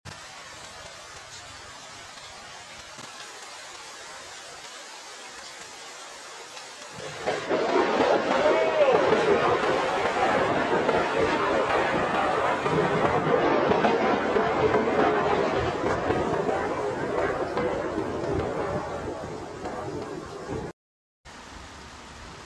AI Thunder sound effects free download